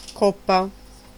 Ääntäminen
IPA : /kæp/ US : IPA : [kæp]